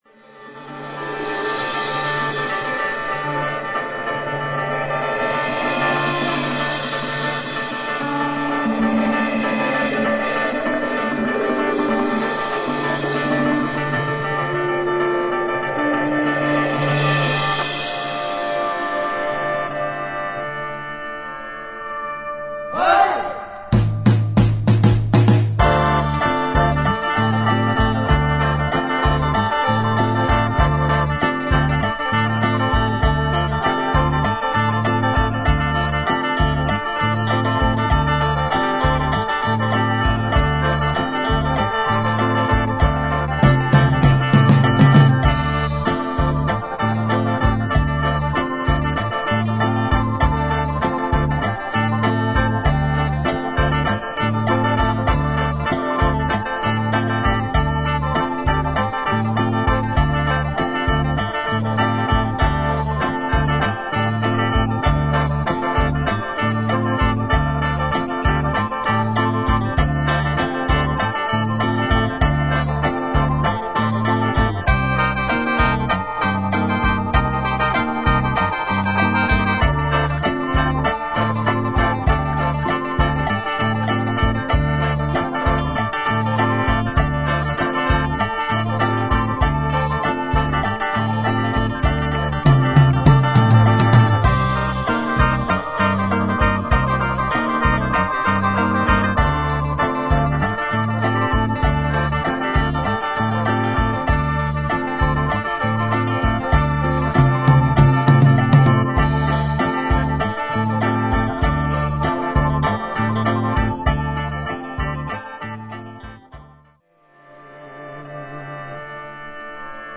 本家タイの音源とは似て非なる中毒性の高い和製ルークトゥン・モーラム（タイの大衆歌謡）を展開。